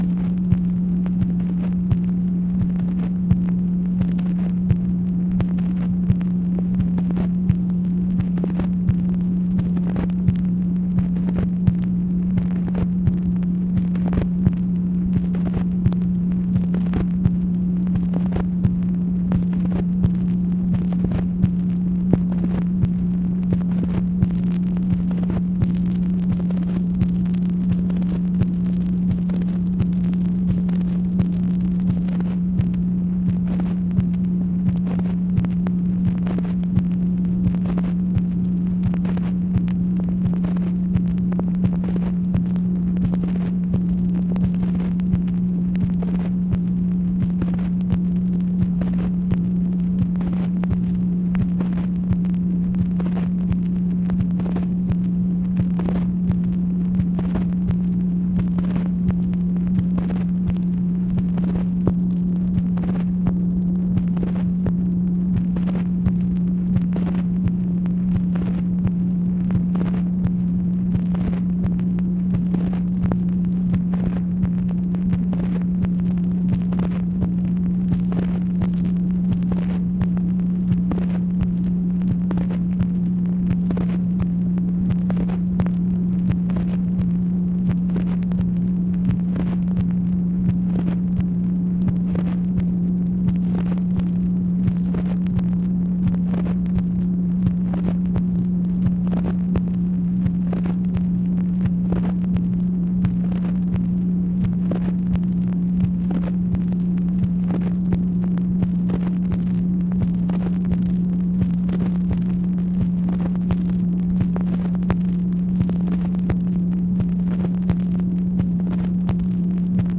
MACHINE NOISE, January 14, 1966